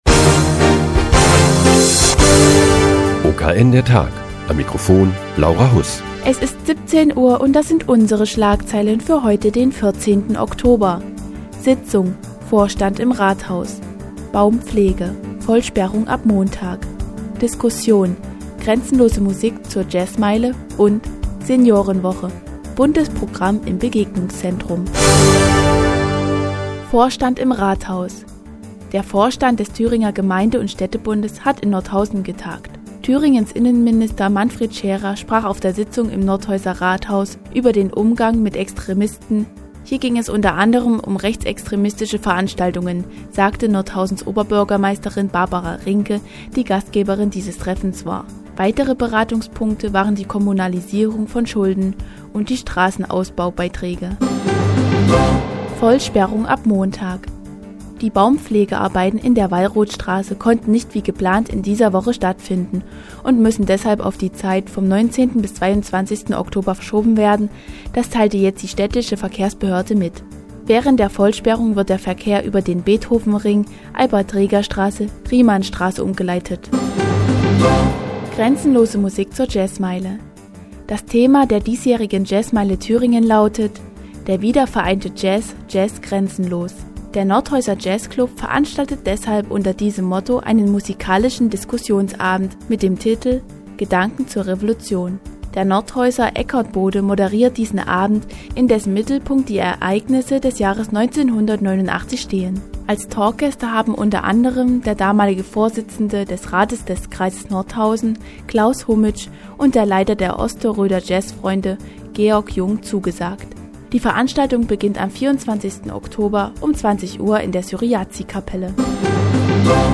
Die tägliche Nachrichtensendung des OKN ist nun auch in der nnz zu hören. Heute geht es um die Vollsperrung der Wallrothstraße und die Jazzmeile Thüringen.